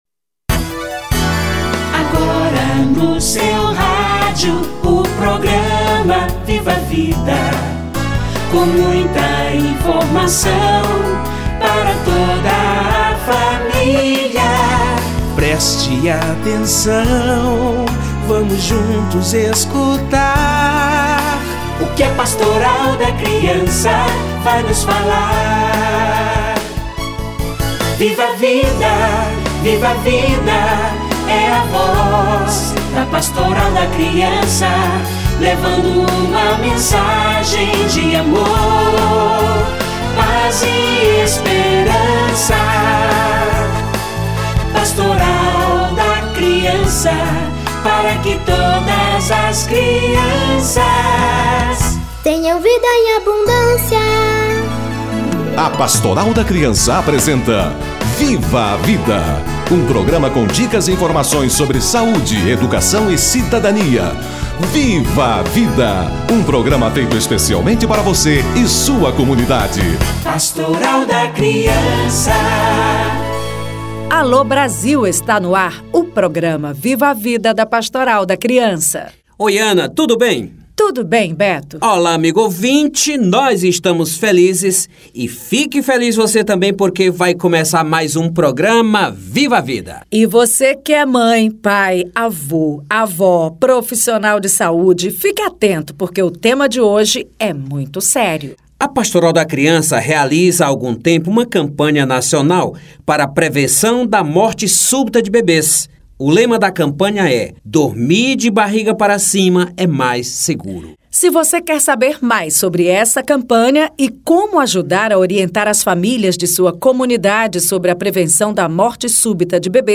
Dormir de barriga para cima é mais seguro - Entrevista